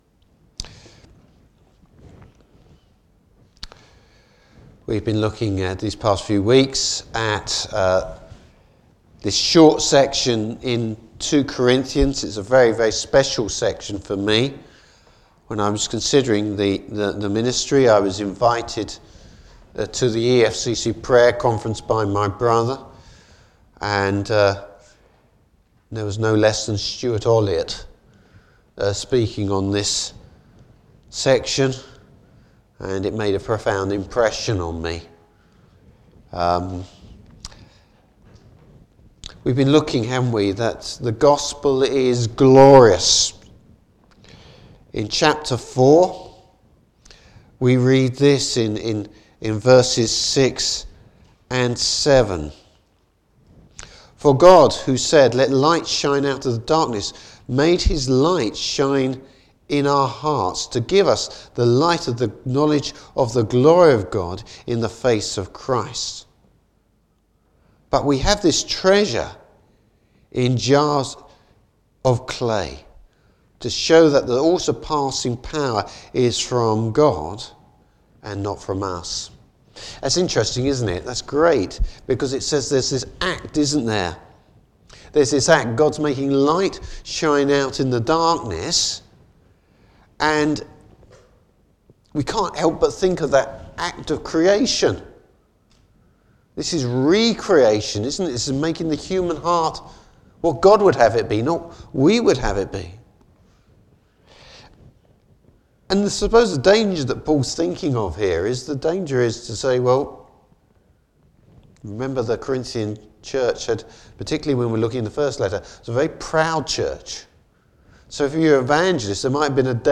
Service Type: Morning Service Bible Text: 2 Corinthians 6-7:1.